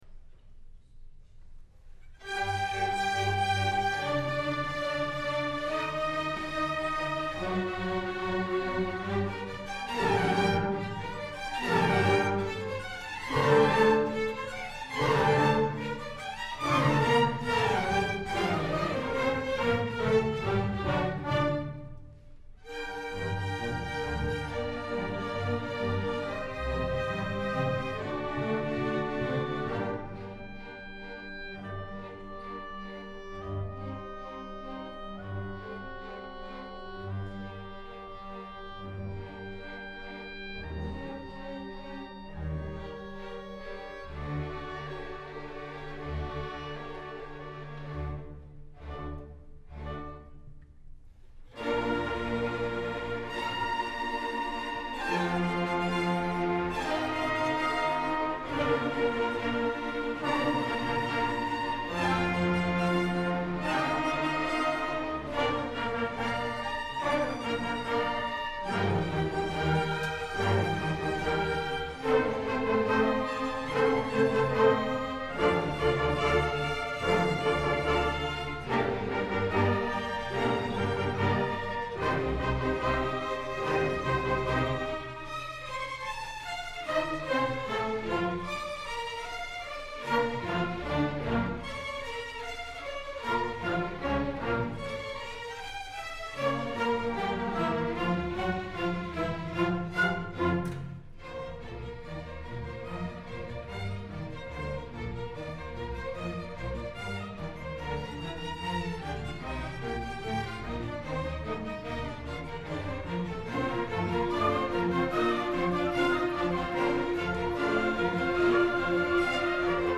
ALNM Chamber Ensemble
Full concert recording